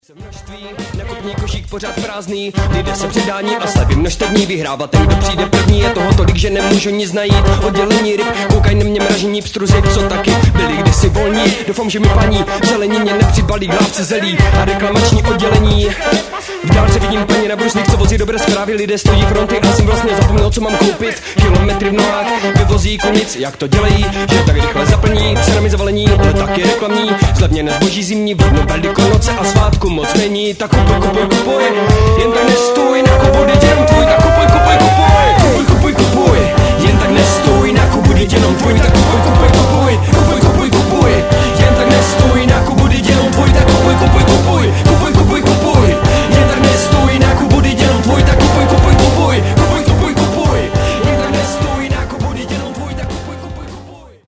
rap blues